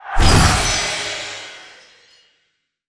spell_harness_magic_08.wav